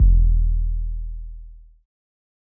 DIS SIDE 808.wav